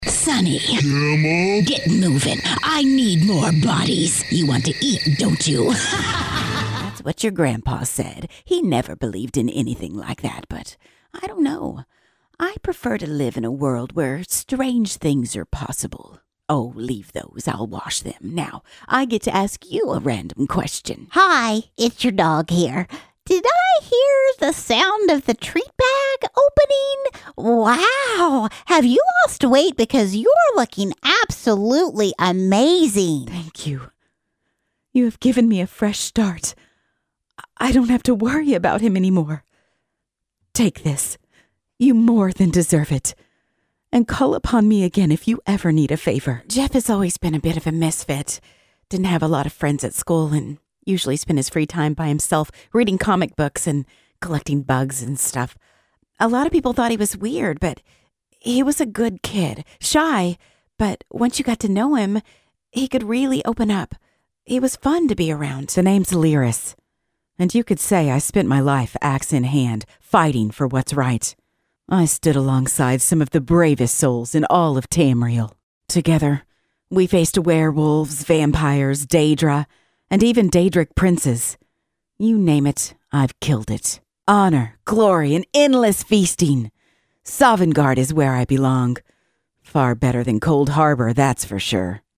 Female Voice Over Talent
Brigtht, Natural, Touch of the South.